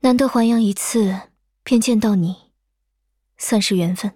【模型】GPT-SoVITS模型编号074_女-secs